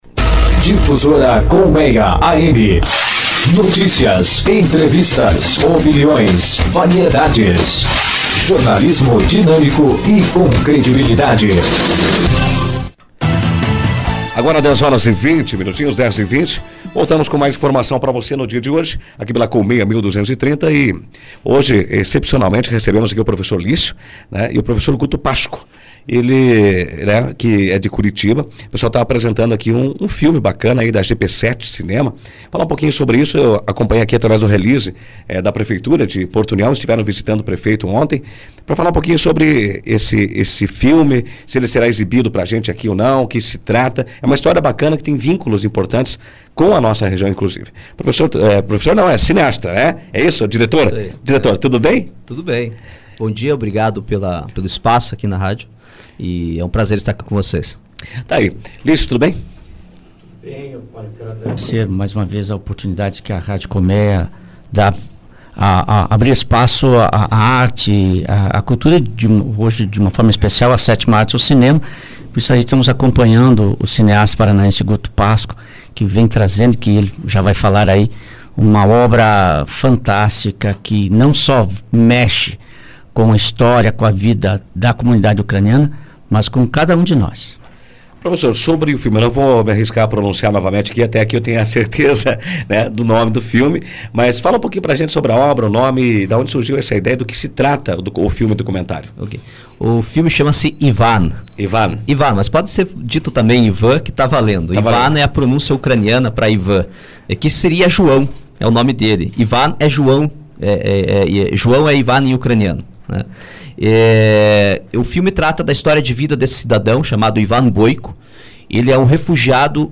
ENTREVISTA-LONGA-METRAGEM-SITE-COLMEIA.mp3